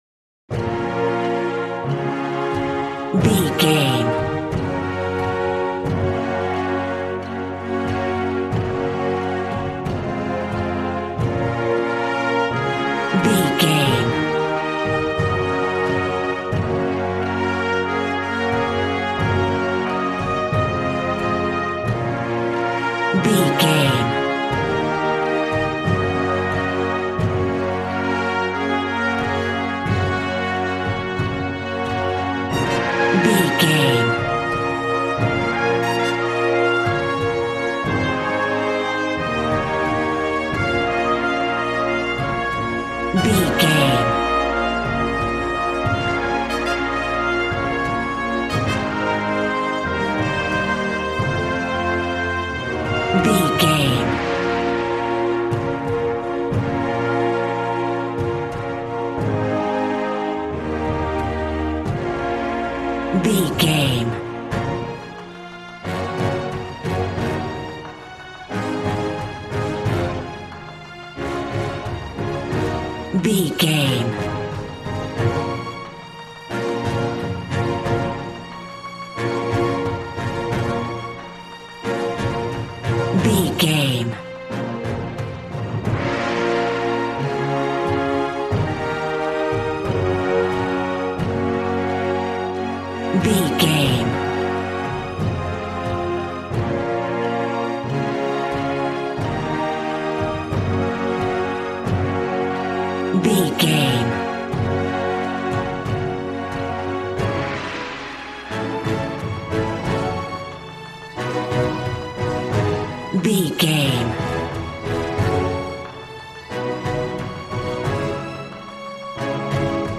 Action and Fantasy music for an epic dramatic world!
Aeolian/Minor
B♭
hard
groovy
drums
bass guitar
electric guitar